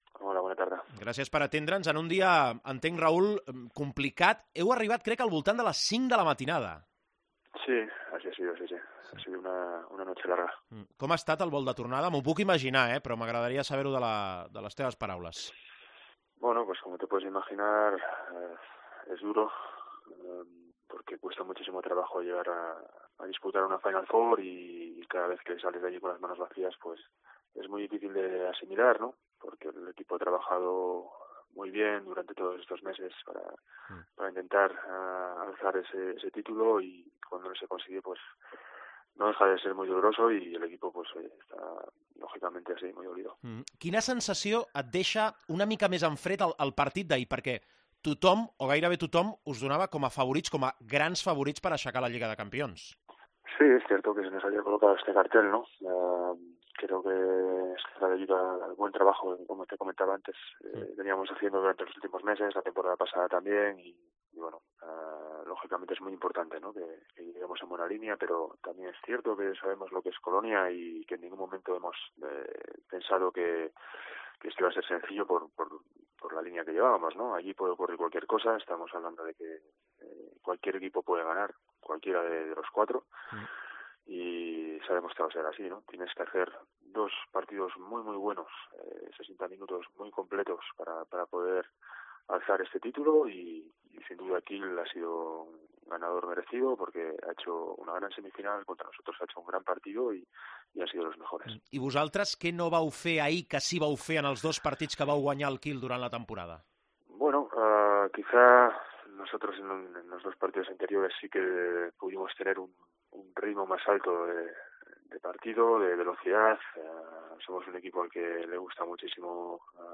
AUDIO: Escolta l'entrevista amb el capità del FC Barcelona després de la derrota contra el Kiel a la final de la Champions.